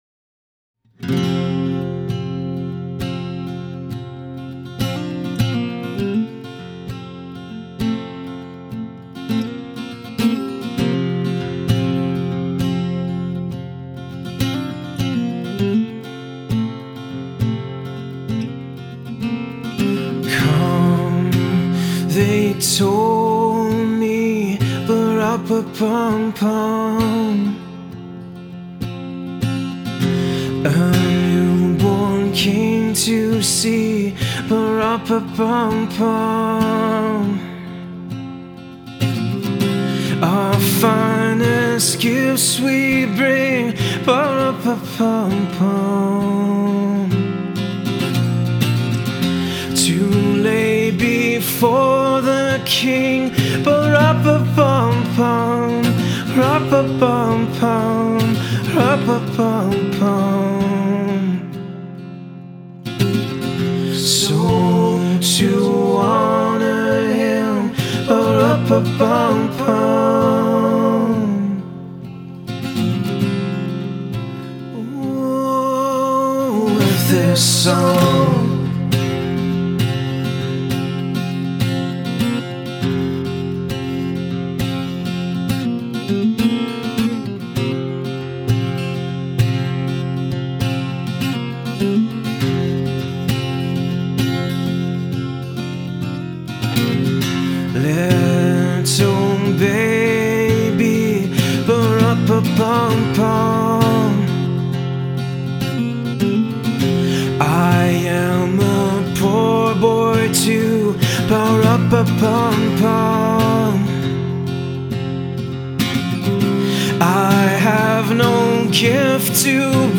Christmas jam